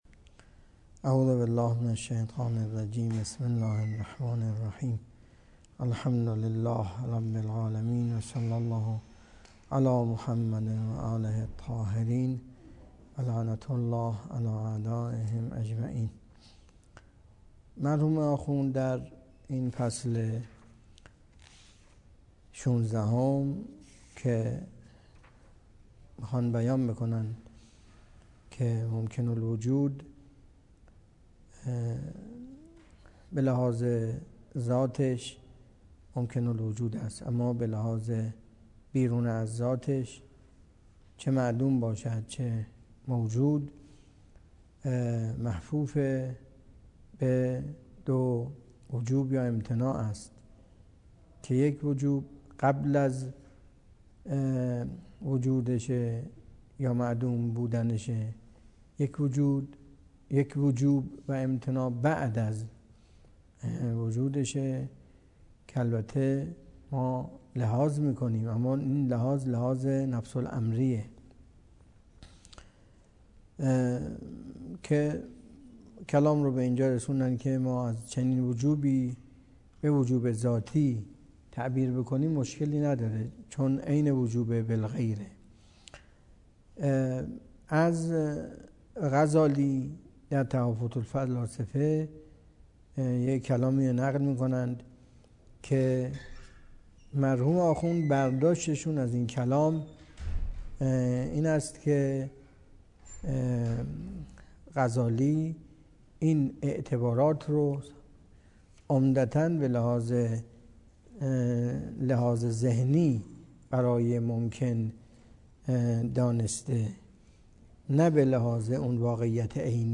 درس فلسفه اسفار اربعه
سخنرانی